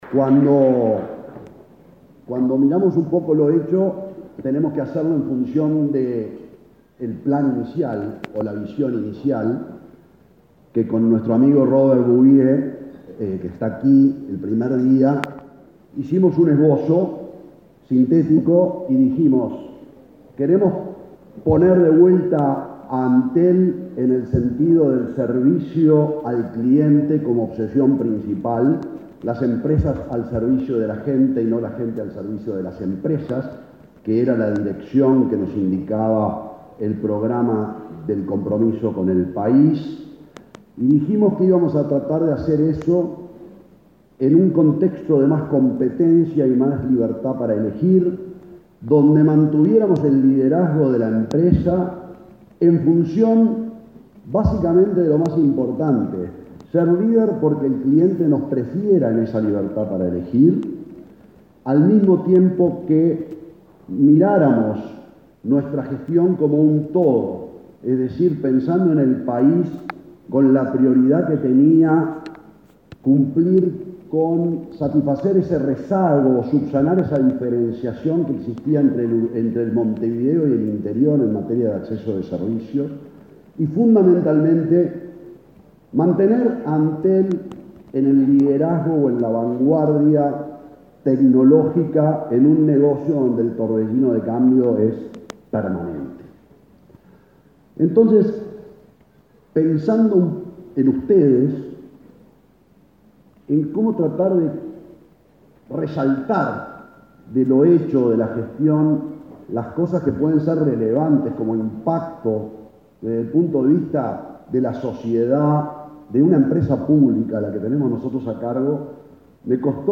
Disertación del presidente de Antel, Gabriel Gurméndez
Disertación del presidente de Antel, Gabriel Gurméndez 04/07/2023 Compartir Facebook X Copiar enlace WhatsApp LinkedIn El presidente de Antel, Gabriel Gurméndez, disertó este martes 4 en Montevideo, en un desayuno de trabajo organizado por la Asociación de Dirigentes de Marketing.